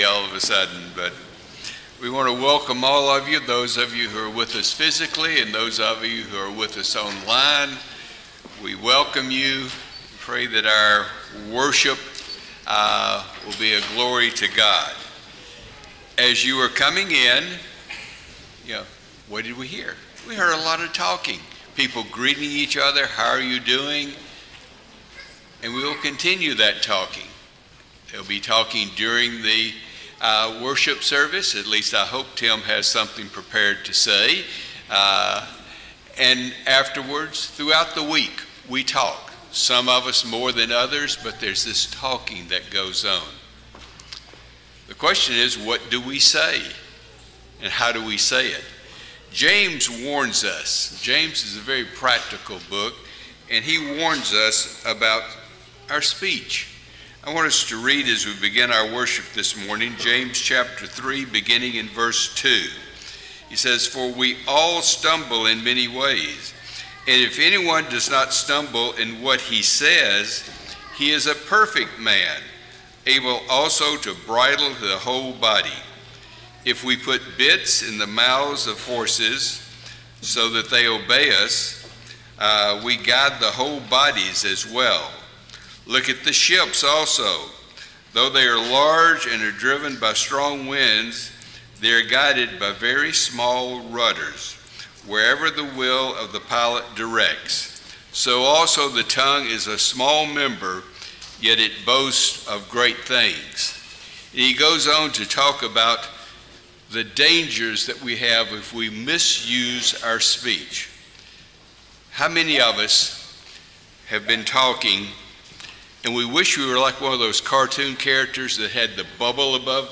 Series: Sunday AM Service